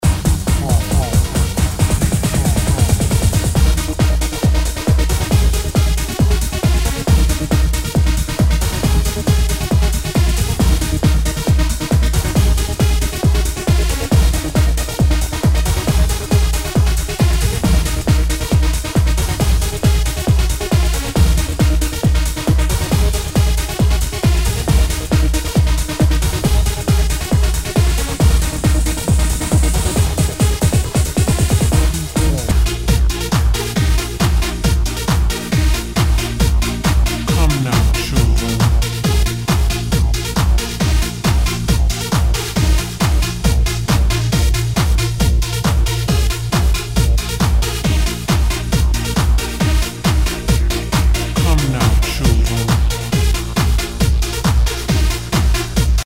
HOUSE/TECHNO/ELECTRO
ナイス！ハード・ハウス！